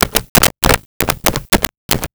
Switchboard Telephone Dialed 02
Switchboard Telephone Dialed 02.wav